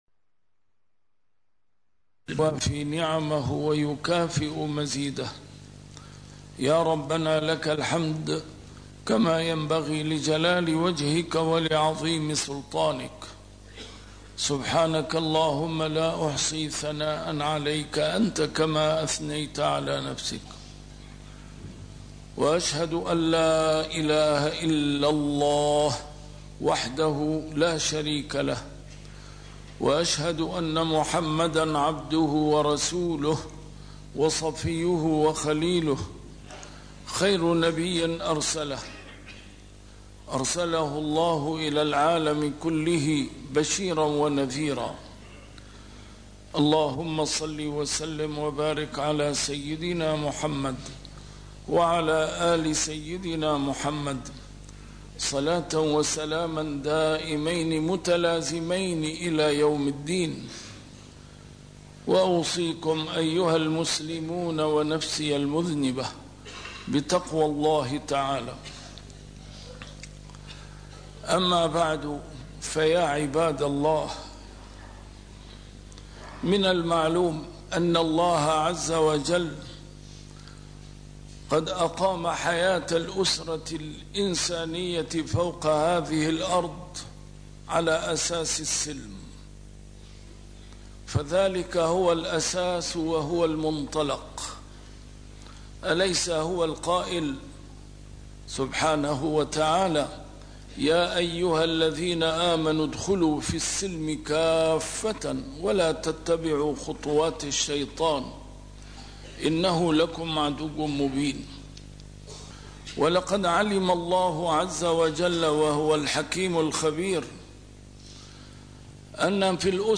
A MARTYR SCHOLAR: IMAM MUHAMMAD SAEED RAMADAN AL-BOUTI - الخطب - السلم